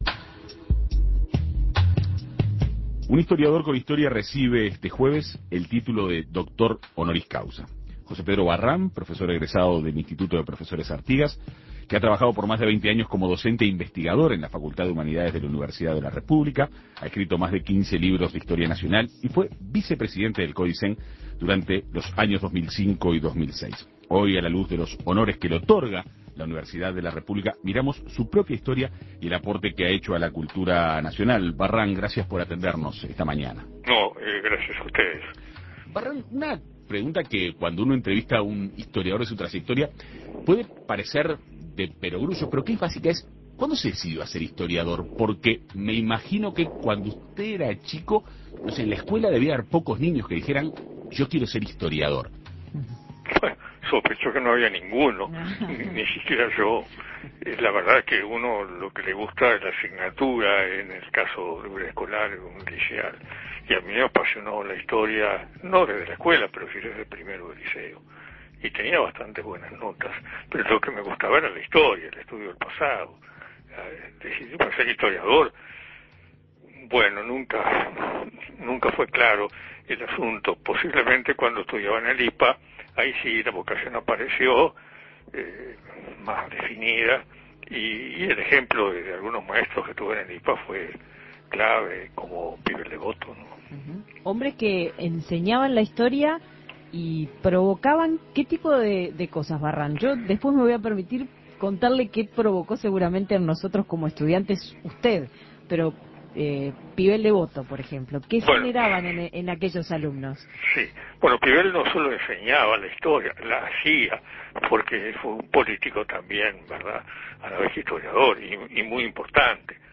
Entrevista con el historiador y ex vicepresidente del Codicen José Pedro Barrán